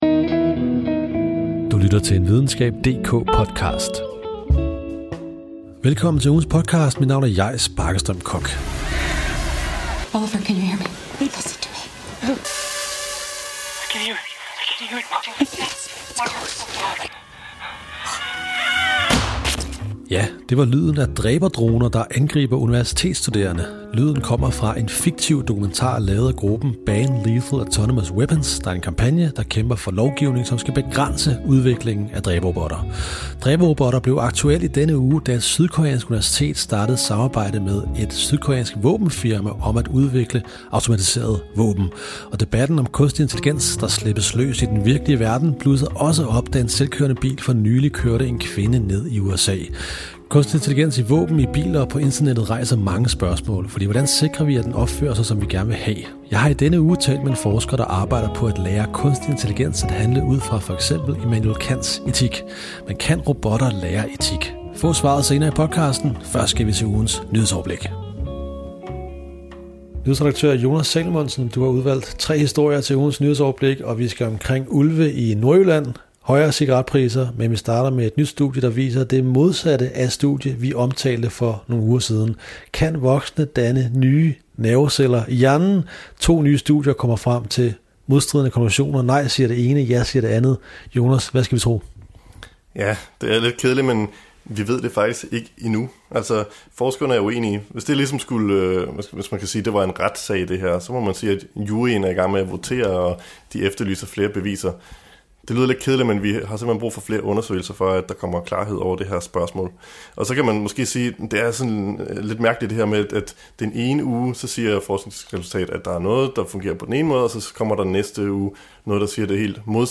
I denne uges podcast diskuterer forskere forholdet mellem robotteknologi og etik. Er det eksempelvis muligt at lære en selvkørende bil at handle etisk korrekt med Kants moralfilosofi?